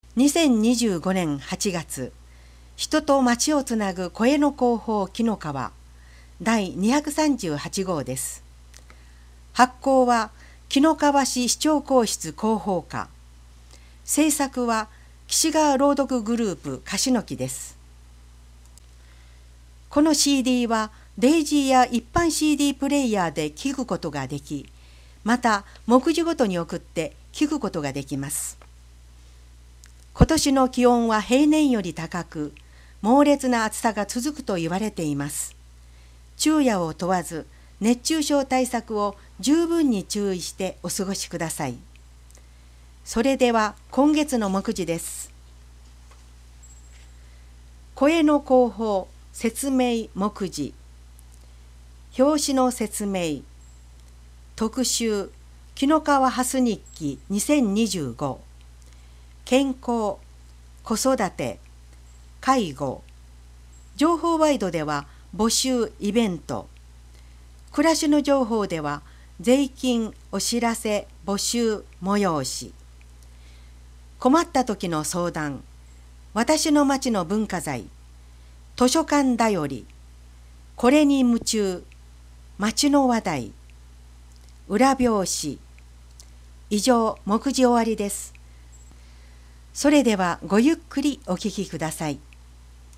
「広報紀の川」の音声版を、MP3形式の音声ファイルでダウンロードしていただけます。